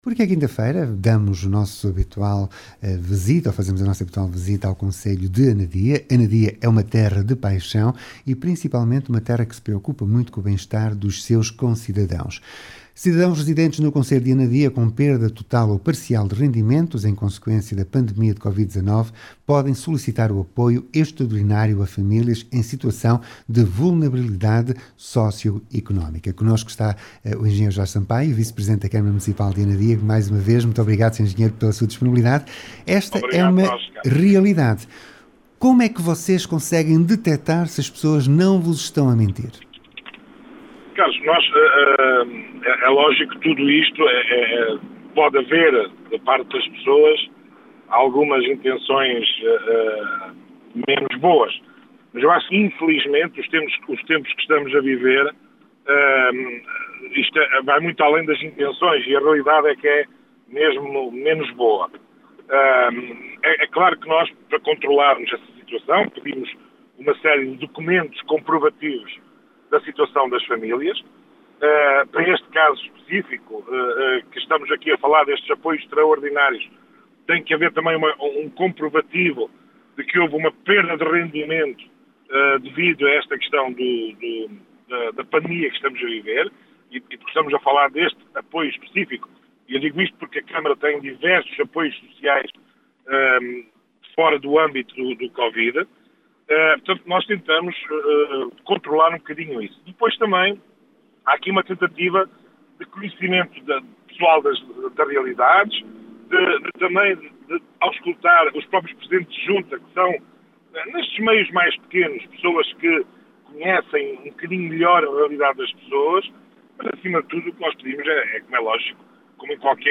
Anadia Terra de Paixão – O apoio extraordinário às famílias e o 8º Concurso Intermunicipal de Leitura foram temas abordados pelo Engenheiro Jorge Sampaio, Vice Presidente da Câmara Municipal de Anadia.